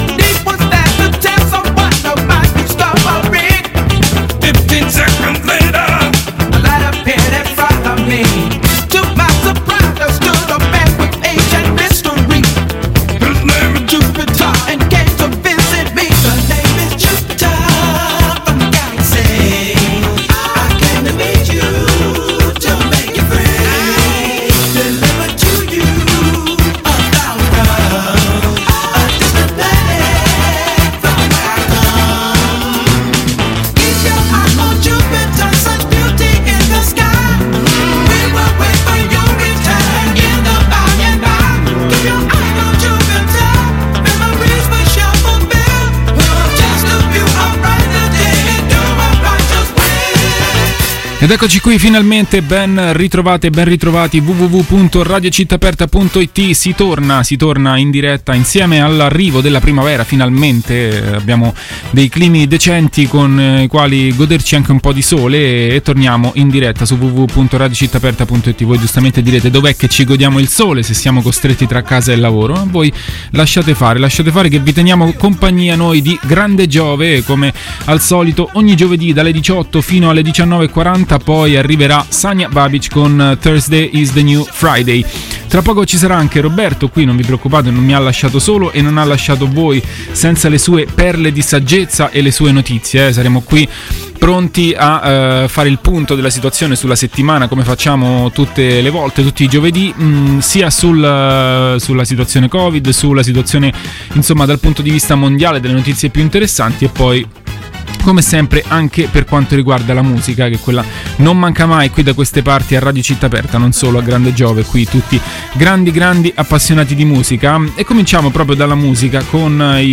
Quasi due ore di news, divertimento e tanta, tanta buona musica.